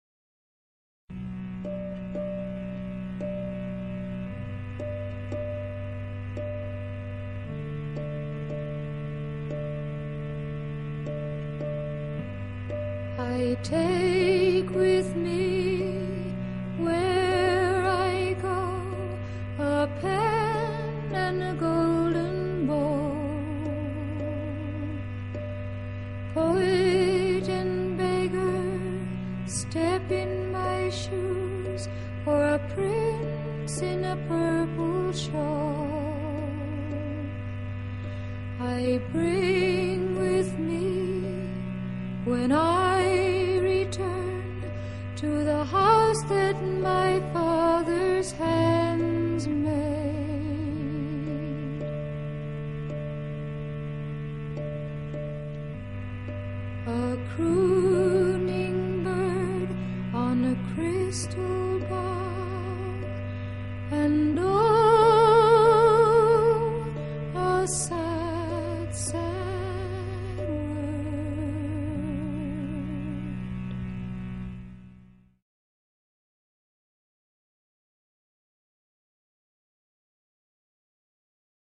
It’s such a short song.
Tags1960s 1968 Eastern US Folk